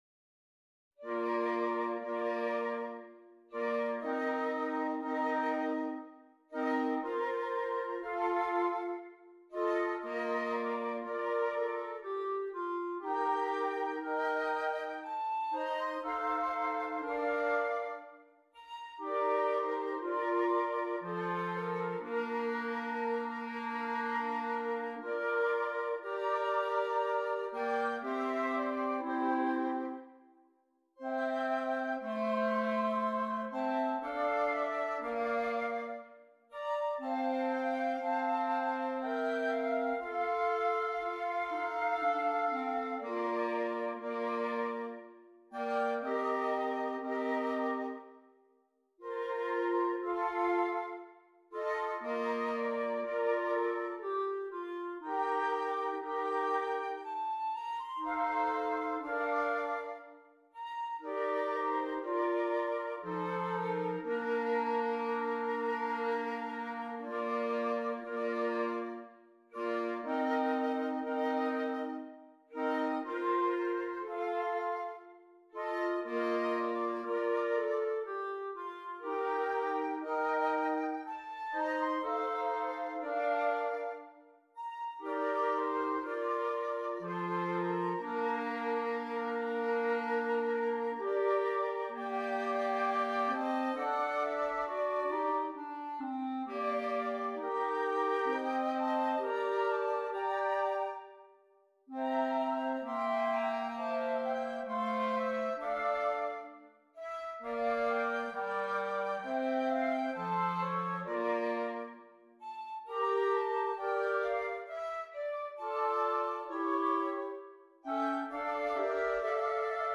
Voicing: Woodwind Quartet